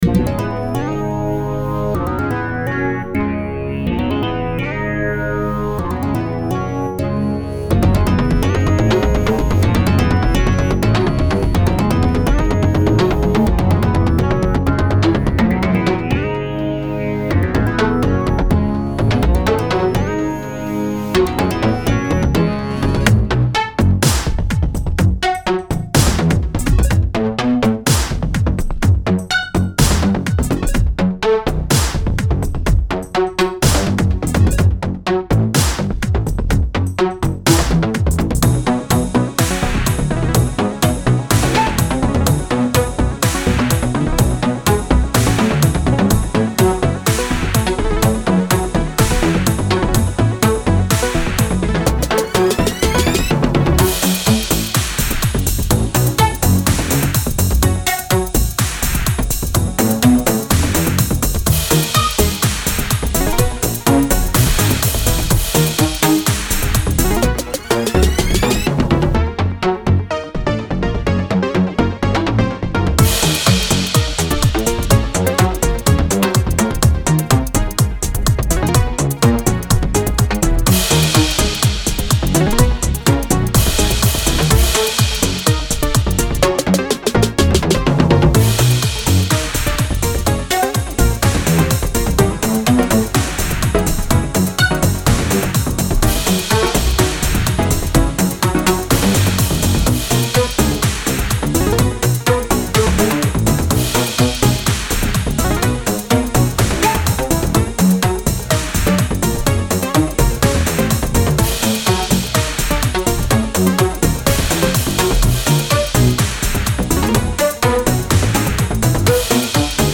so 80's and epic, really nice drum samples and very catchy